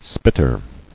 [spit·ter]